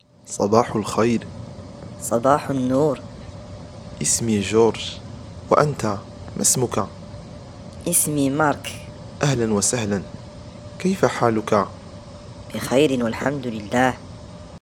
DIALOGUE-1-U1.mp3